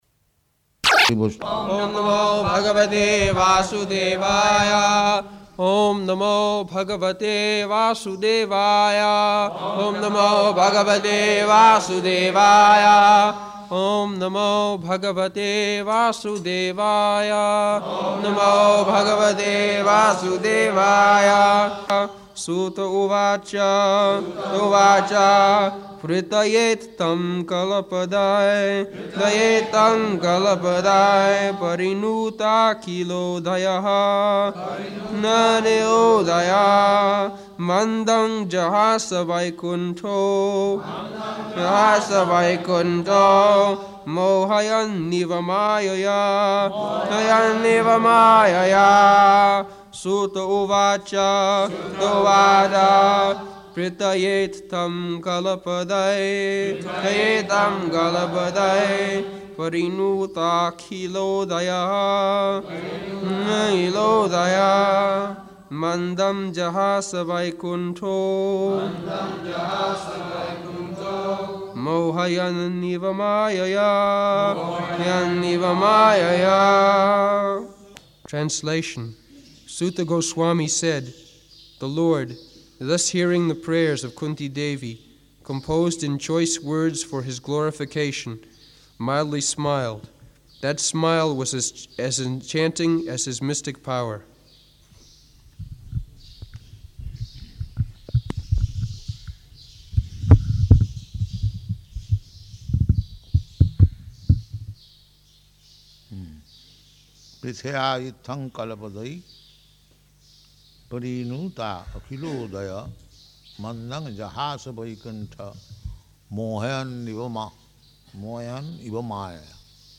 October 24th 1974 Location: Māyāpur Audio file
[devotees repeat] [leads chanting of verse, etc.]